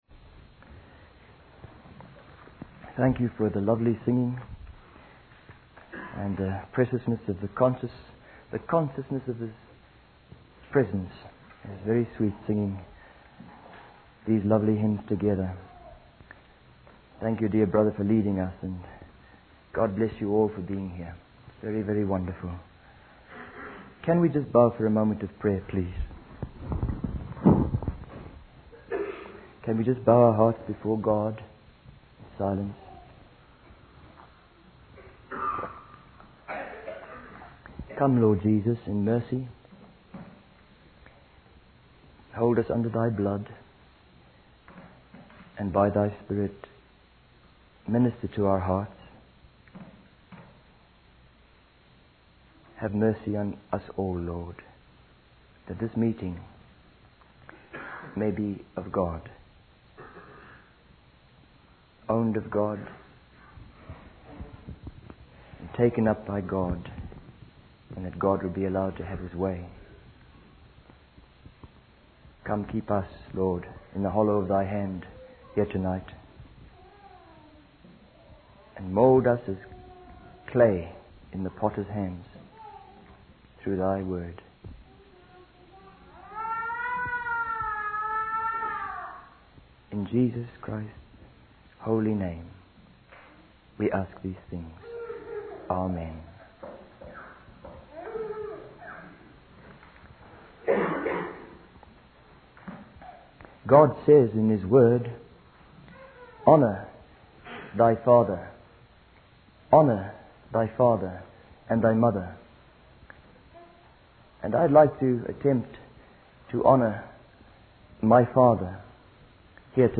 In this sermon, the speaker shares the story of his father's journey towards salvation.